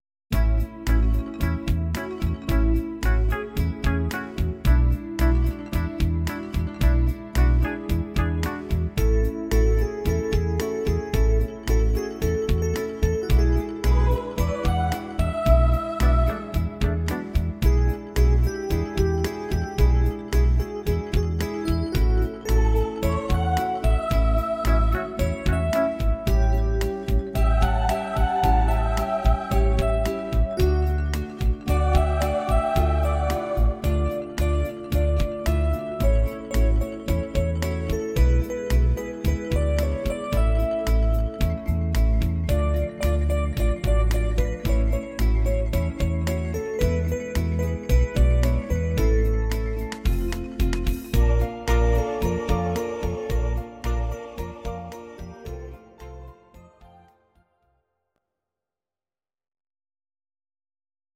Audio Recordings based on Midi-files
Ital/French/Span, 1960s